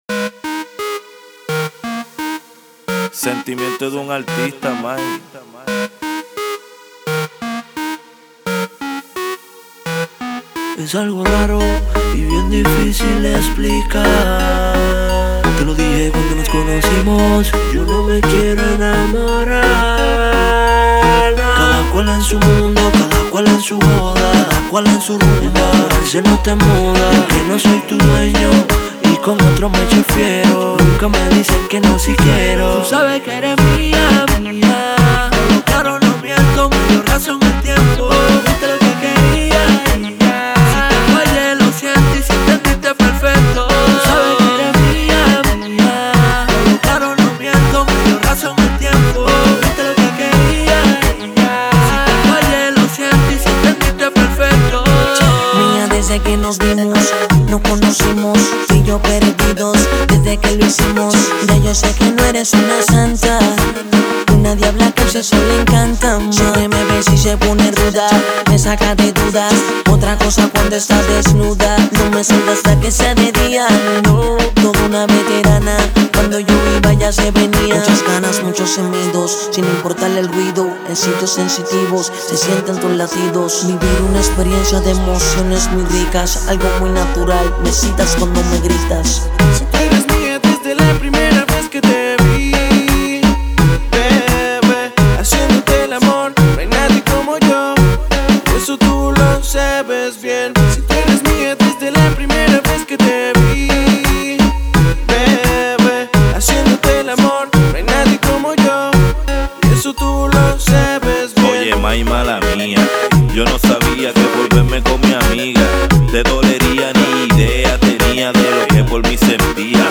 reggaeton music